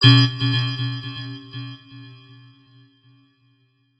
Âm thanh Cảnh báo lỗi vận hành
Thể loại: Tiếng chuông, còi
Description: Âm thanh Cảnh báo lỗi vận hành là âm thanh thông báo cho người quản lý biết đang có sự cố xảy ra đối với máy móc, âm thanh cảnh báo lỗi thiết bị, đây là hiệu ứng âm thanh báo động cho người quản lý biết máy móc đang không được vận hành trơn tru mà xảy ra vấn đề cần xử lý.
Am-thanh-canh-bao-loi-van-hanh-www_tiengdong_com.mp3